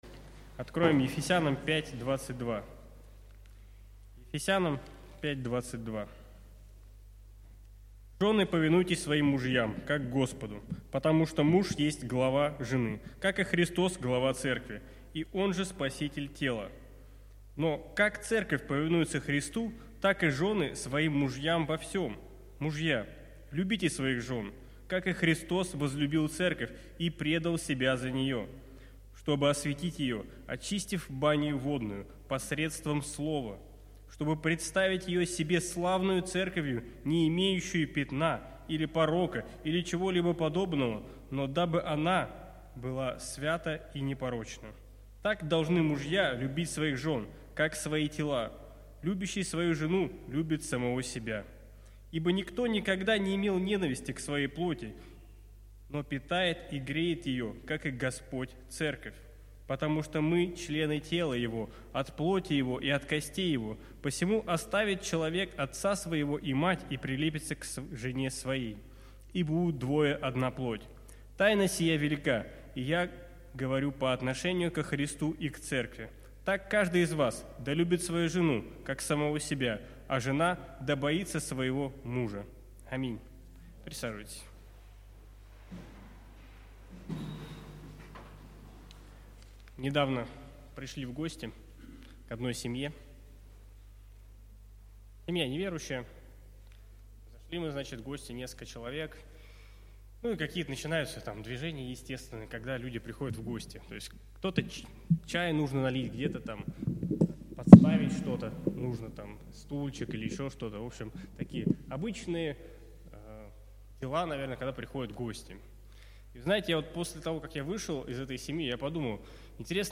Проповеди